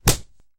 Звук падающего мяса на деревянную поверхность